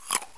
eat-crunch1.wav